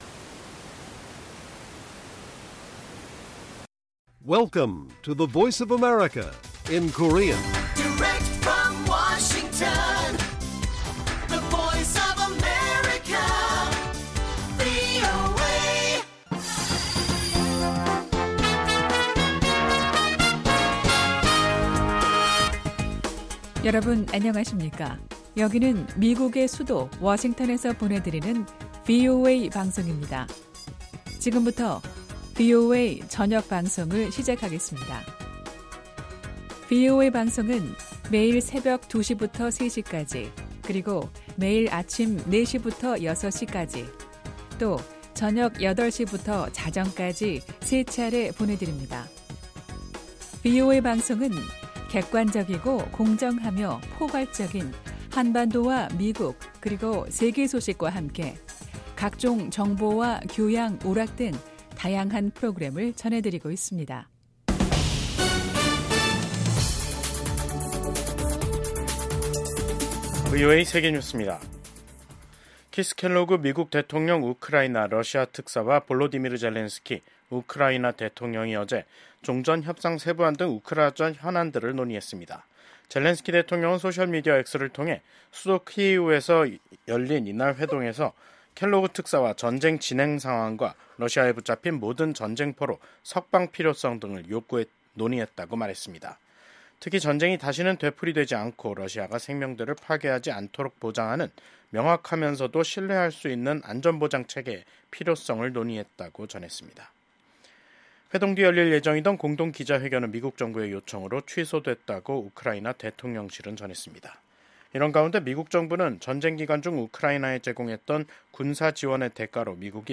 VOA 한국어 간판 뉴스 프로그램 '뉴스 투데이', 2025년 2월 21일 1부 방송입니다. 미국 백악관 국가안보보좌관은 도널드 트럼프 대통령이 김정은 북한 국무위원장을 비롯한 독재자들에 맞설 수 있는 유일한 인물이라고 밝혔습니다. 러시아에 파병된 북한군의 전투력이 낮아 우크라이나 군에 압도당하고 있다고 전 유럽주둔 미군 사령관이 평가한 가운데 유엔 인권기구는 우크라이나군에 생포된 러시아 파병 북한군을 본국으로 돌려보내서는 안 된다는 입장을 밝혔습니다.